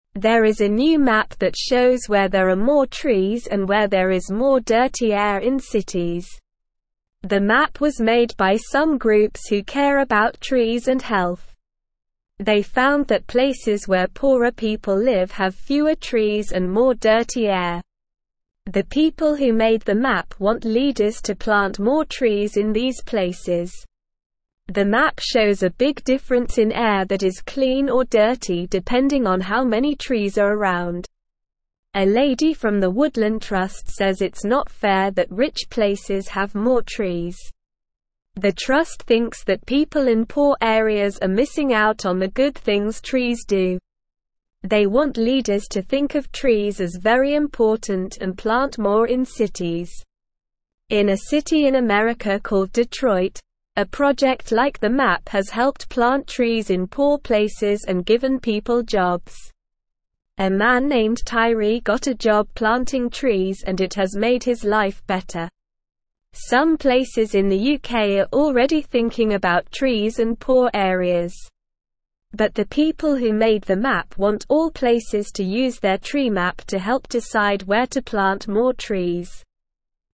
Slow
English-Newsroom-Lower-Intermediate-SLOW-Reading-Map-Shows-Places-with-Few-Trees-Have-Dirty-Air.mp3